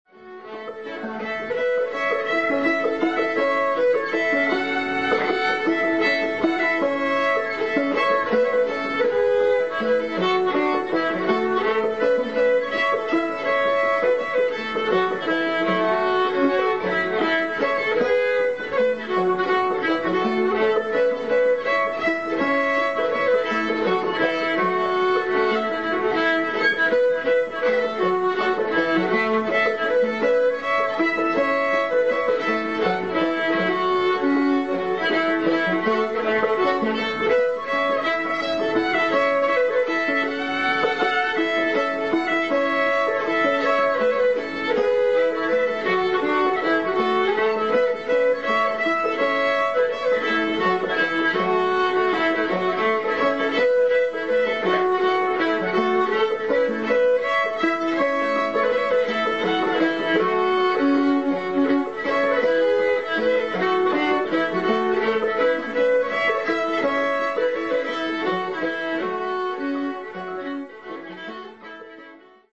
Fiddle
Guitar